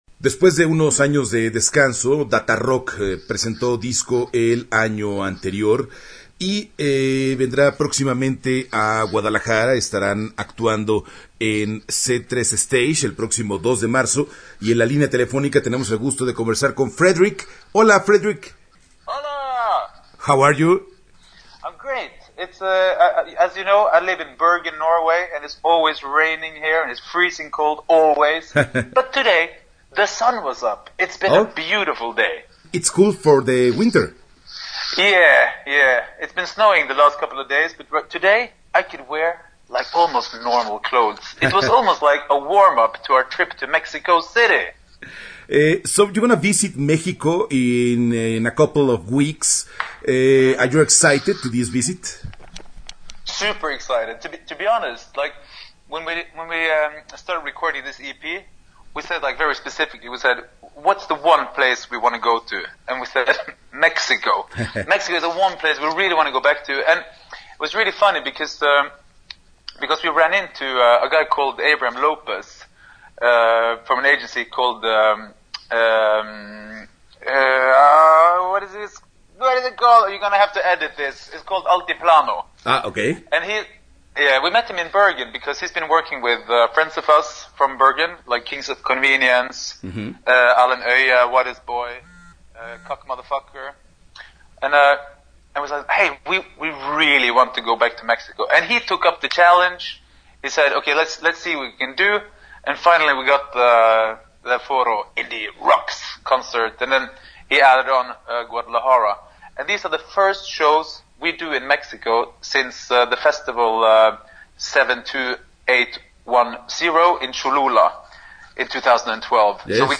Entrevista-Datarock-C3-Stage-2019-web.mp3